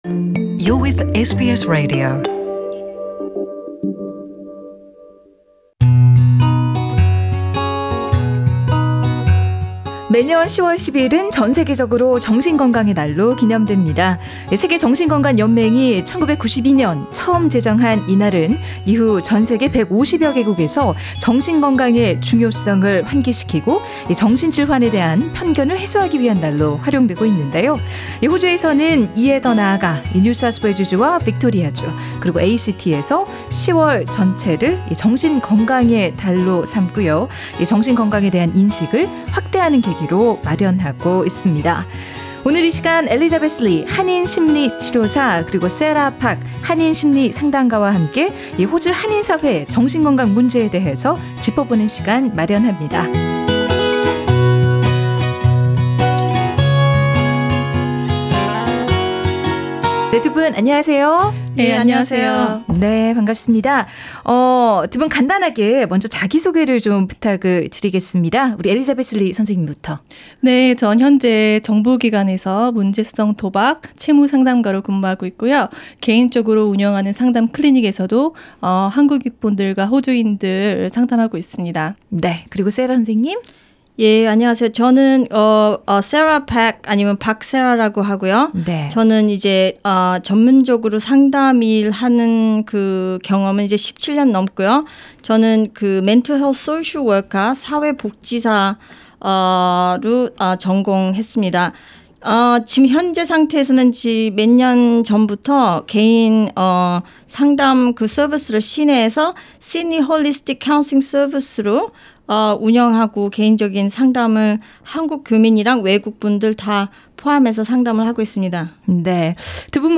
[상단의 팟캐스트를 통해 전체 인터뷰를 들으실 수 있습니다] Share